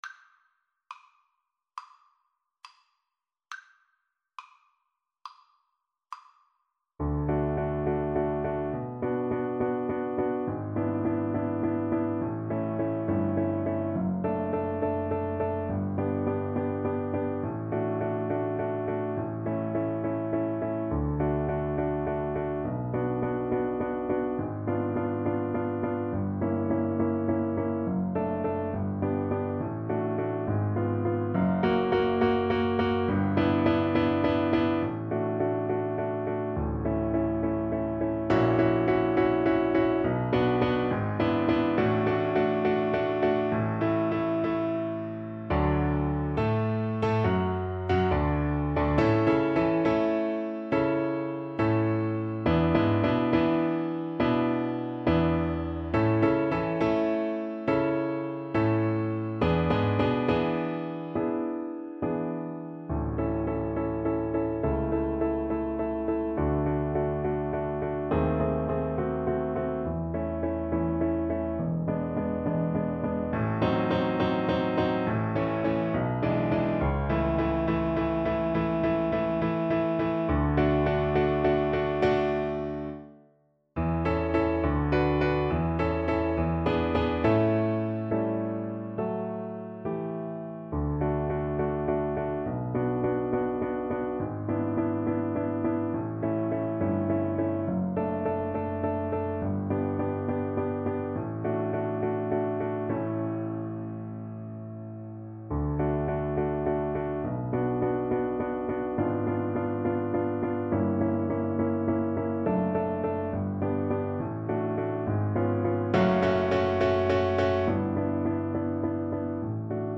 Play (or use space bar on your keyboard) Pause Music Playalong - Piano Accompaniment Playalong Band Accompaniment not yet available reset tempo print settings full screen
D major (Sounding Pitch) (View more D major Music for Violin )
Classical (View more Classical Violin Music)